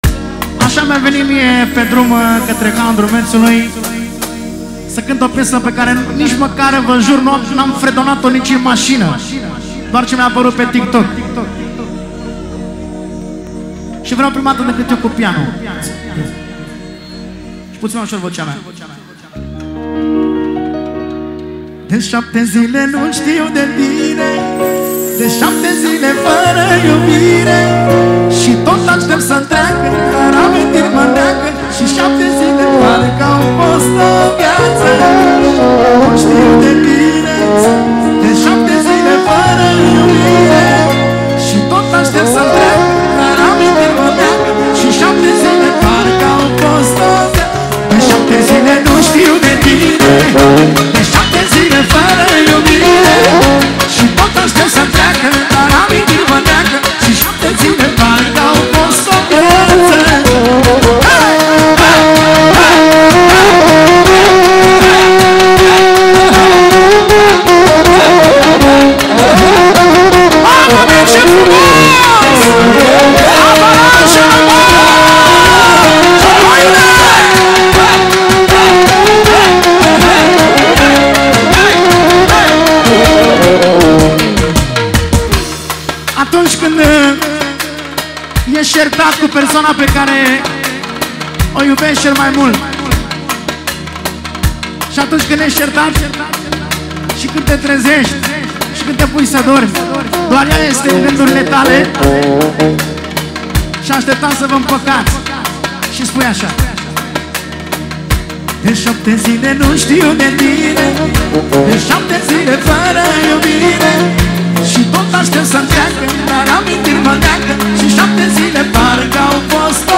o piesă care îmbină emoție și ritm
Muzica Usoara